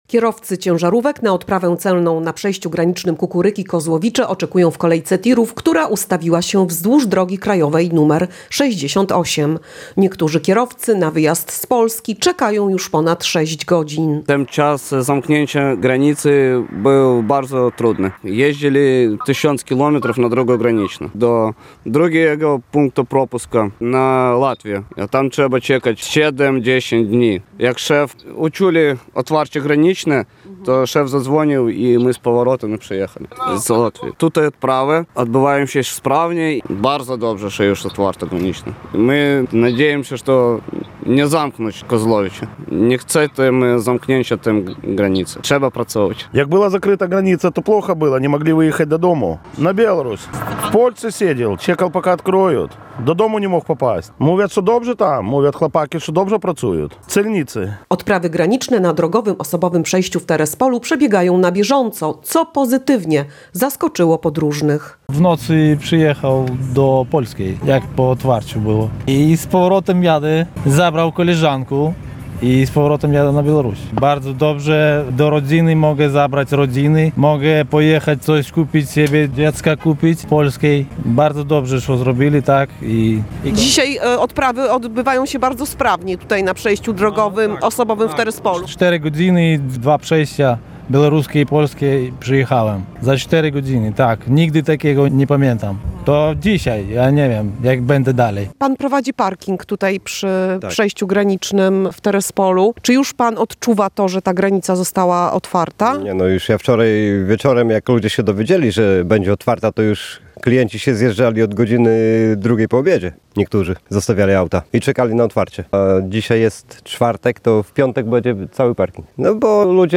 – Ten czas zamknięcia granicy był bardzo trudny – mówi jeden z kierowców.